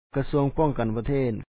kasúaŋ pɔ̀ɔŋ-kan pathèet Ministry of Defense